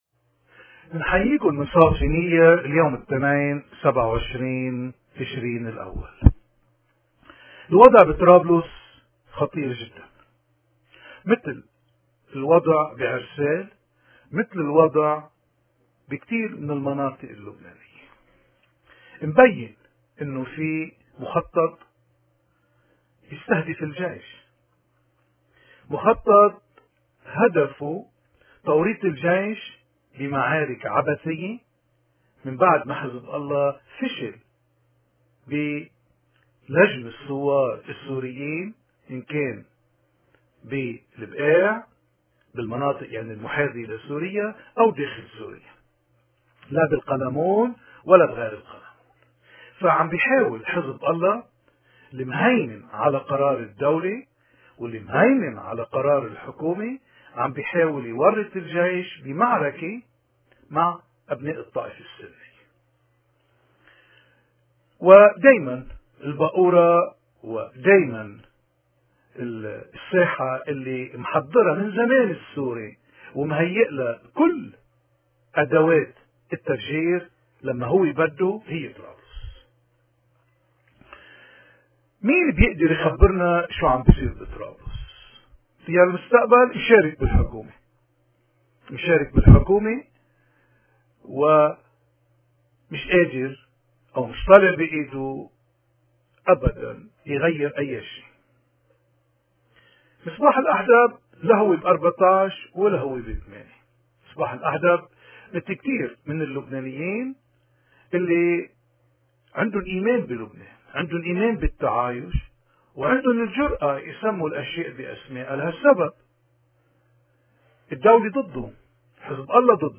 نشرة الأخبار العربية ليوم 28 تشرين الأول/2014